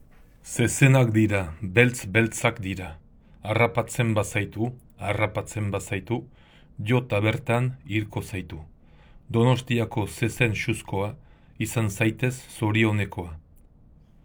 pronounciation
zezenak-pronounciation.wav